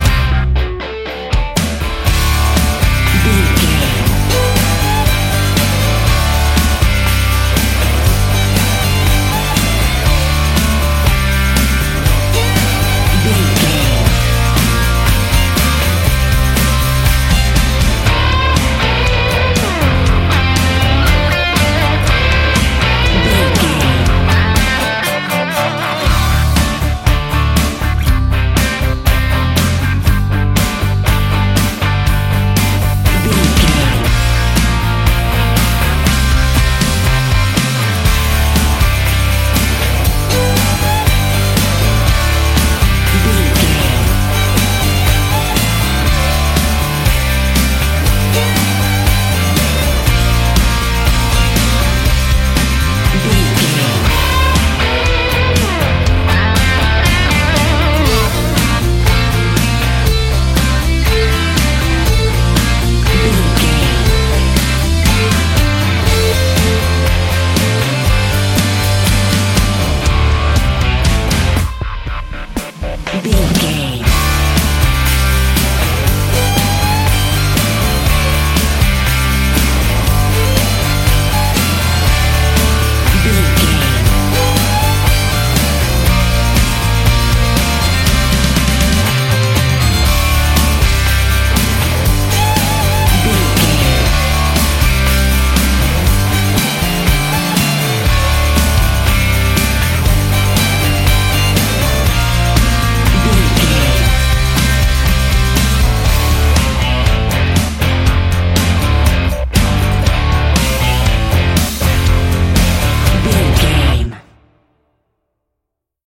Aeolian/Minor
drums
electric guitar
bass guitar
violin